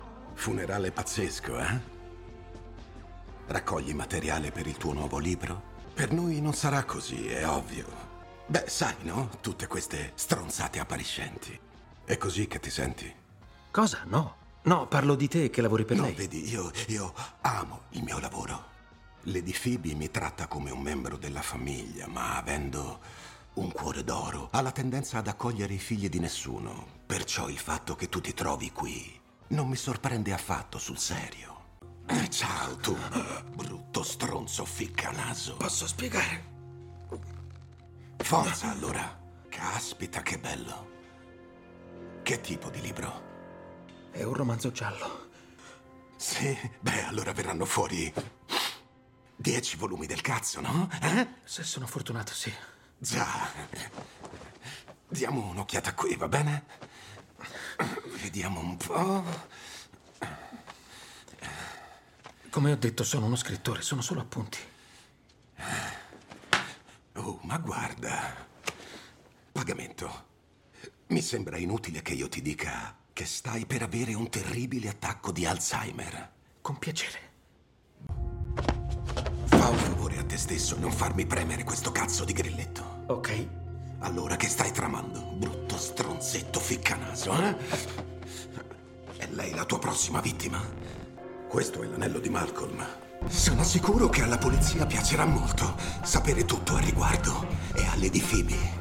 nel telefilm "You", in cui doppia Sean Pertwee.